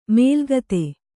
♪ mēlgate